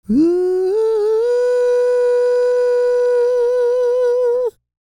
E-CROON P330.wav